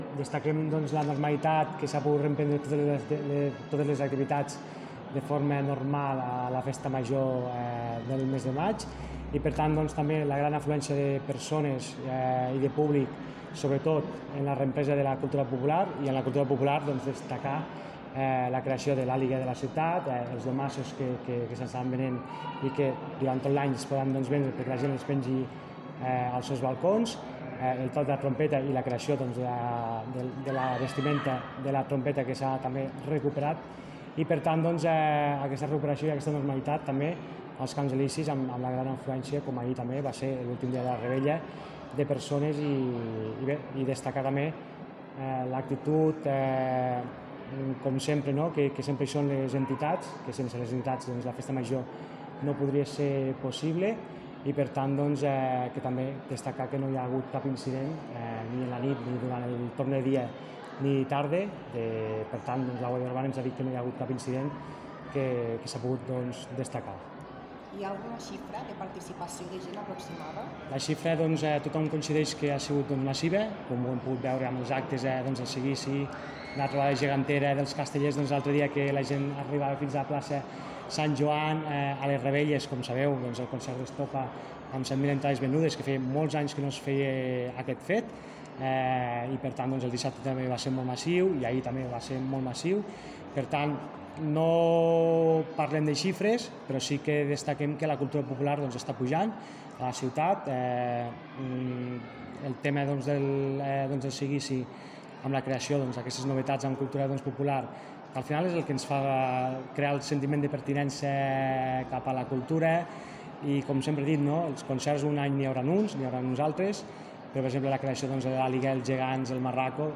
tall-de-veu-del-regidor-de-festes-i-tradicions-ignasi-amor-de-balanc-de-la-festa-major-de-lleida